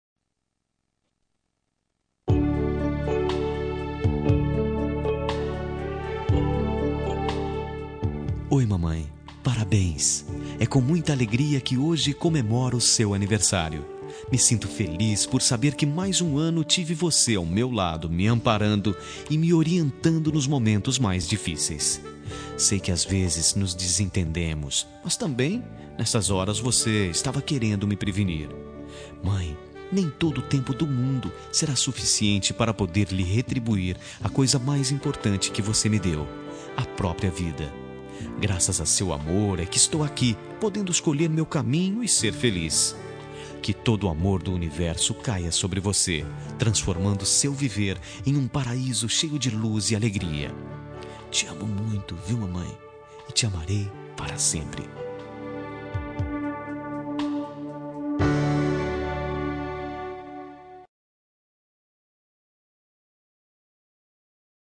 Telemensagem de Aniversário de Mãe – Voz Masculina – Cód: 1438